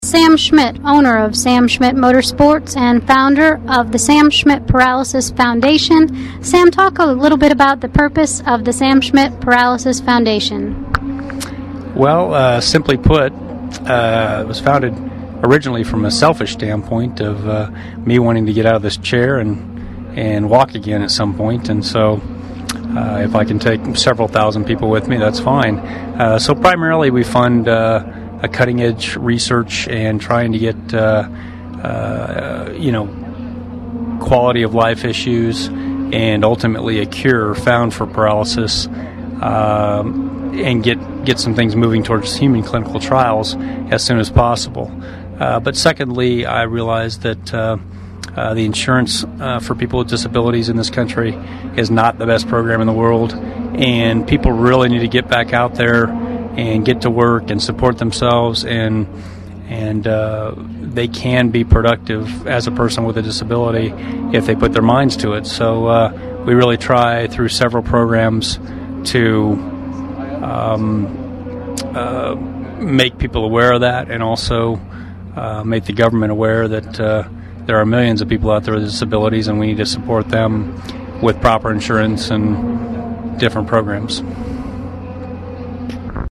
radiointerview.mp3